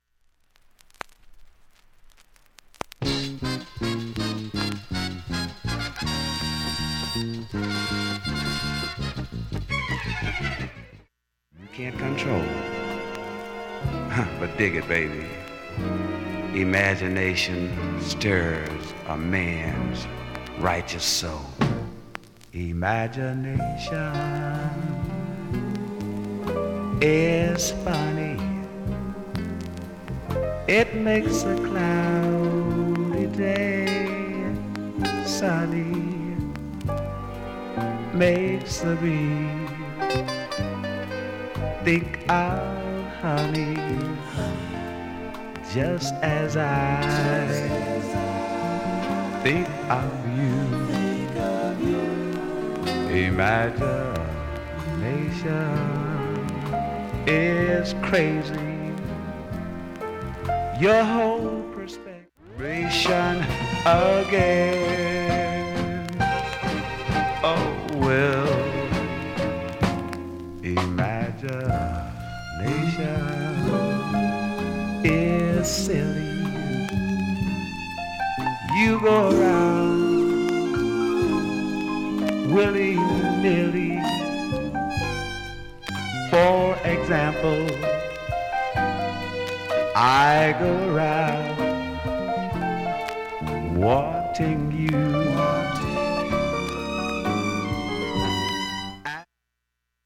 クリアな音質良好全曲試聴済み。
A-1始めにかすかプツが３回出ます。
周回プツ出ますがかすかです。
６回までのかすかなプツが2箇所
３回までのかすかなプツが12箇所
単発のかすかなプツが8箇所
甘く、スタンダードを中心に歌ったアルバム